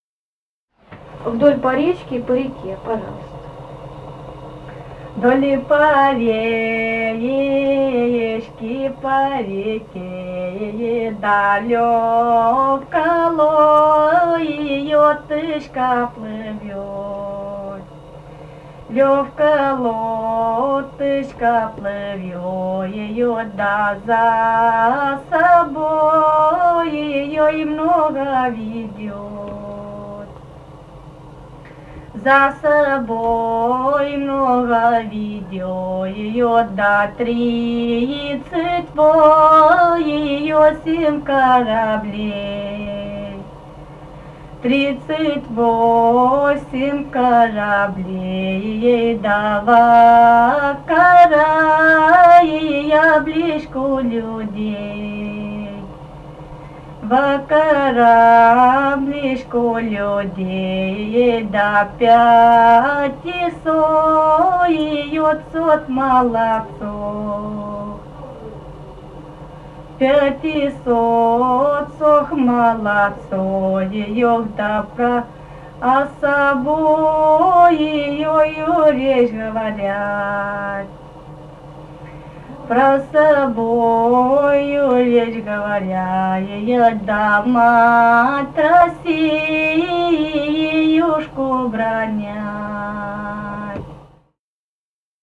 Этномузыкологические исследования и полевые материалы
«Вдоль по речке, по реке» (историческая).
Ставропольский край, с. Бургун-Маджары Левокумского района, 1963 г. И0727-18